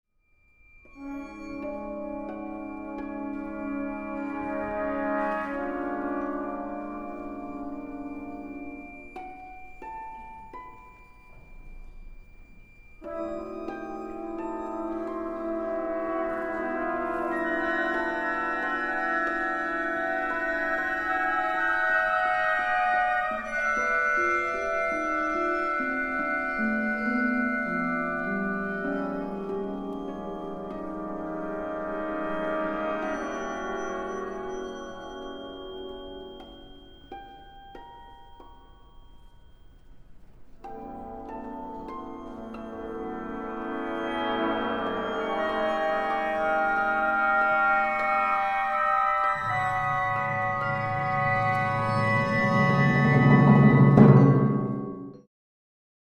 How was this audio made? Audio excerpts from the world premiere